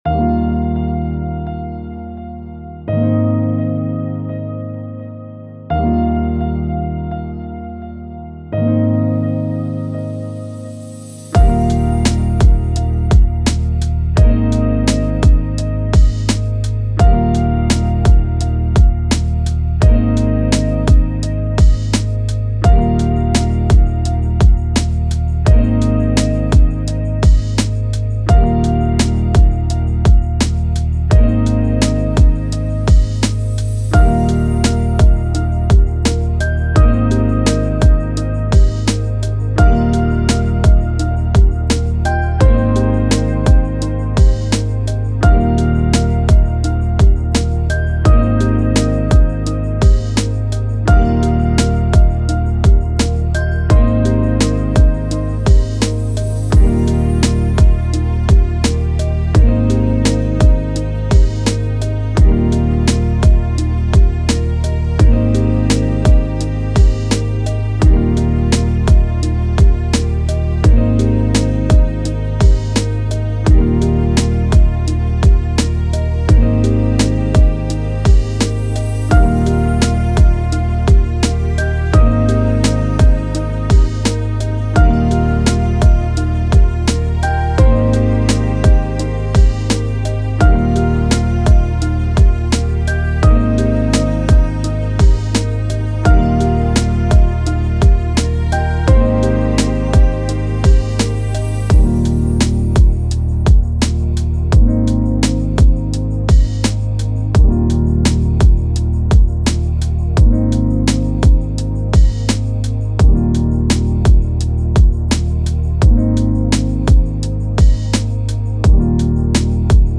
The Ambient Music.mp3